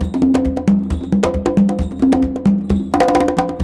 CONGABEAT6-R.wav